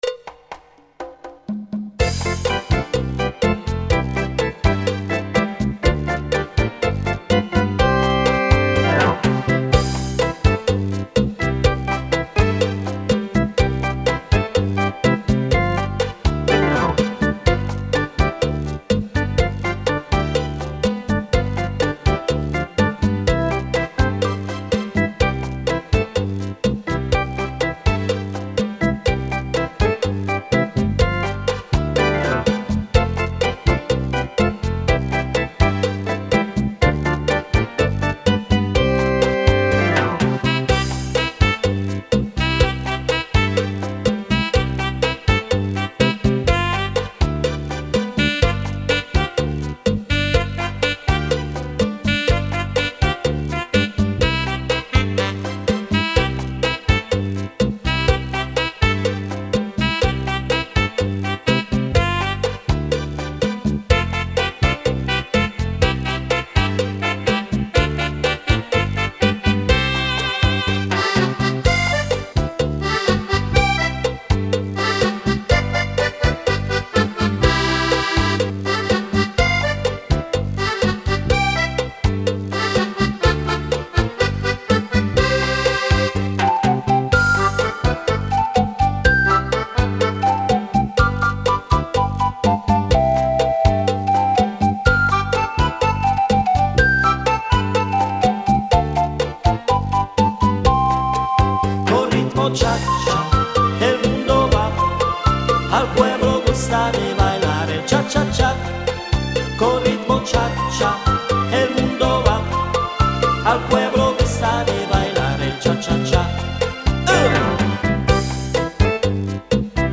Canzoni e musiche da ballo
cha cha cha